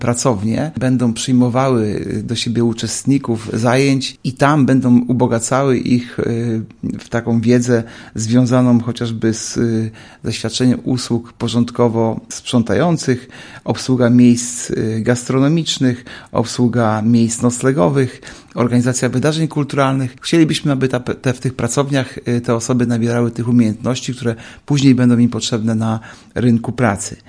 – Pokoszarowy budynek zostanie wyremontowany – mówi Artur Urbański, zastępca prezydenta Ełku.